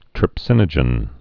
(trĭp-sĭnə-jən)